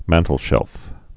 (măntl-shĕlf)